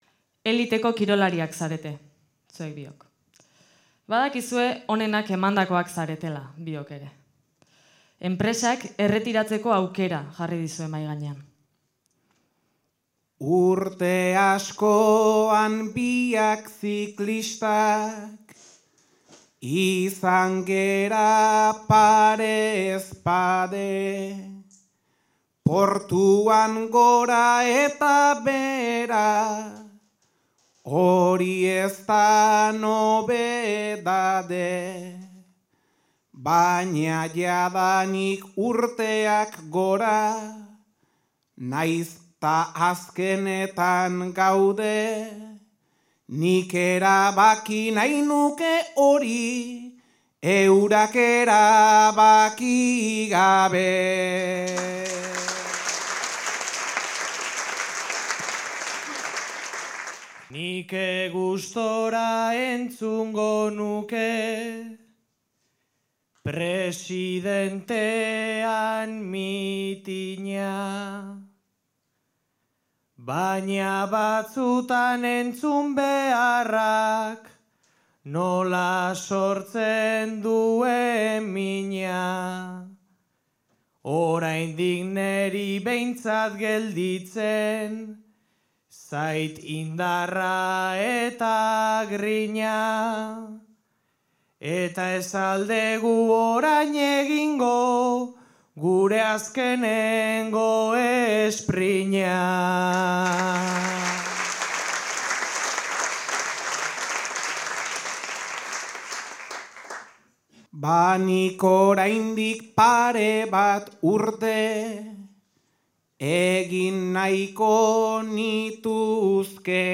Zarautz (Gipuzkoa)
Zortziko handia.